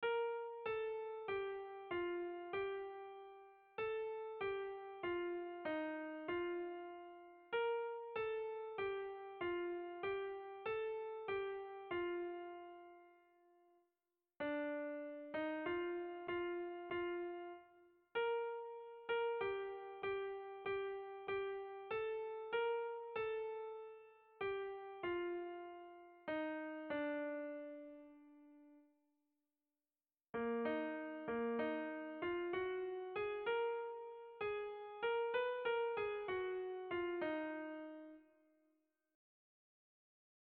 Irrizkoa
Eibar < Debabarrena < Gipuzkoa < Euskal Herria
AB